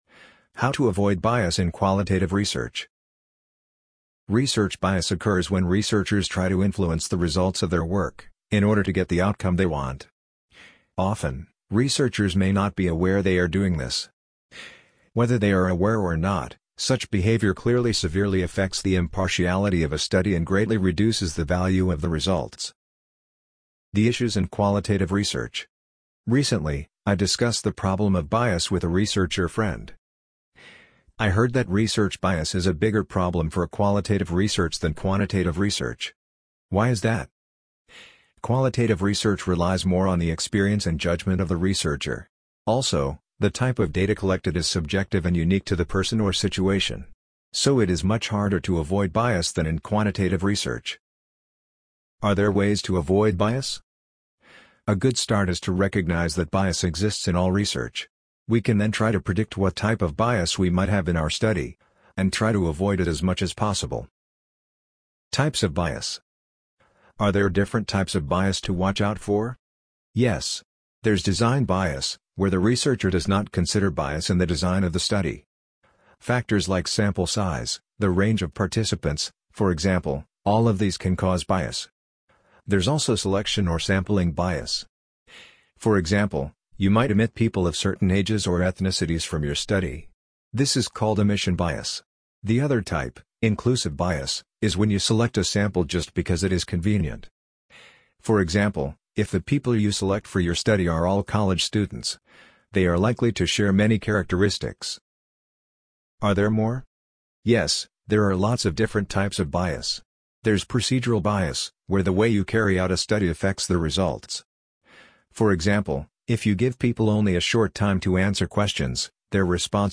amazon_polly_132.mp3